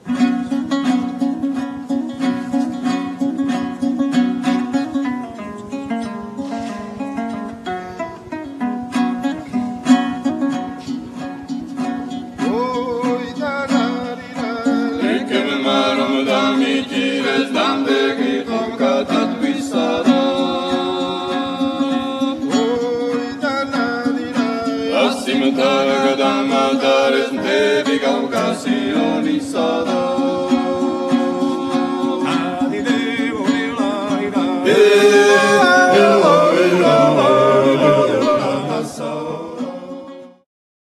Nagranie koncertowe.